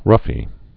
(rŭfē)